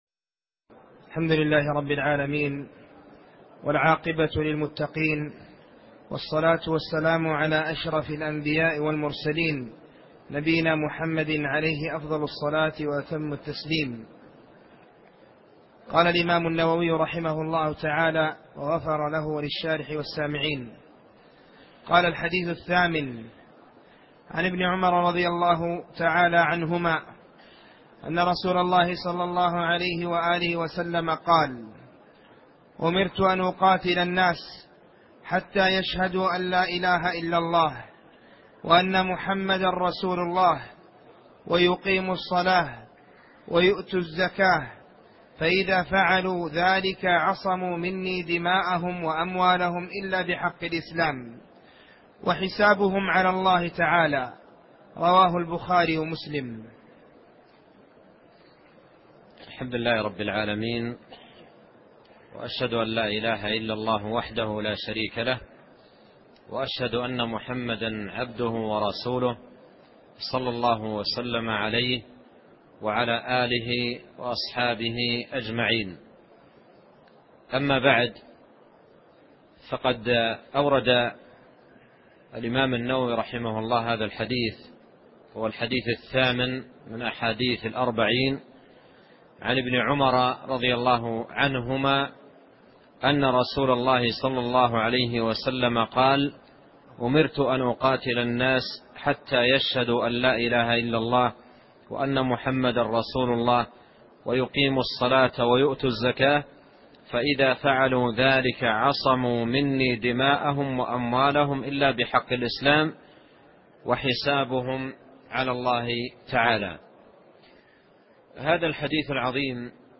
شرح الأربعون النووية الدرس الحادي عشر